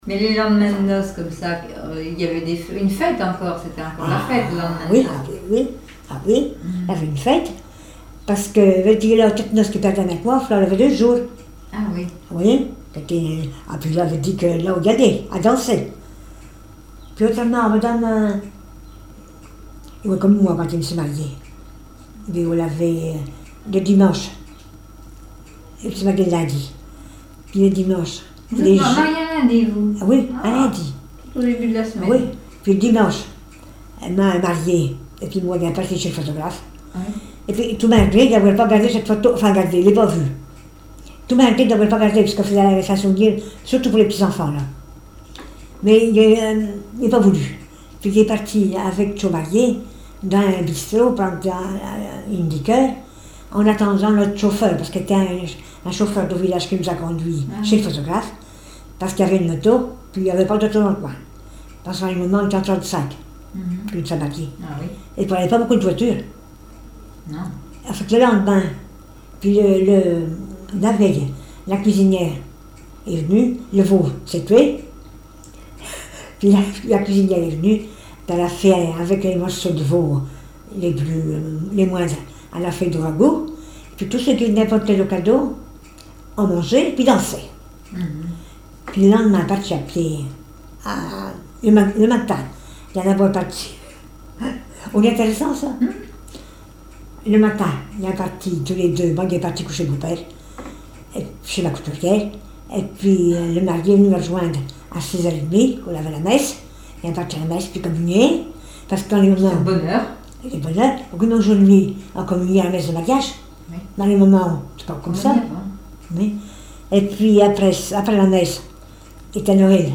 Témoignages sur la vie à la ferme